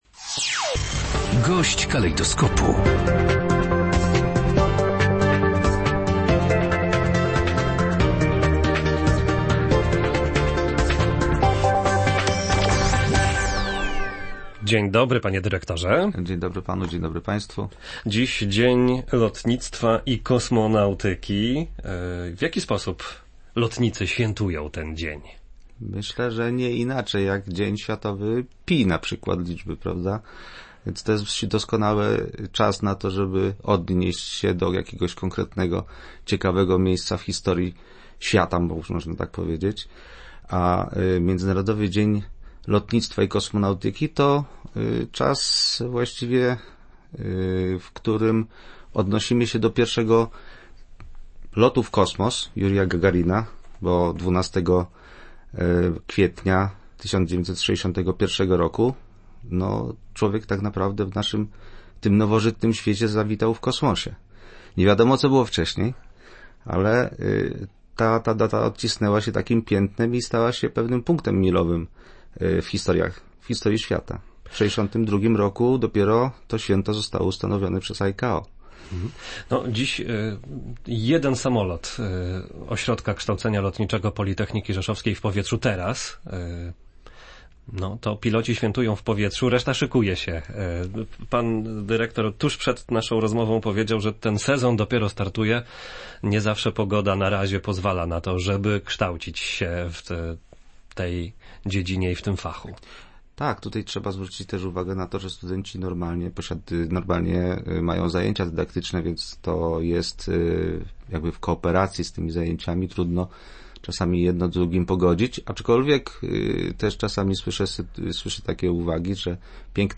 rozmowie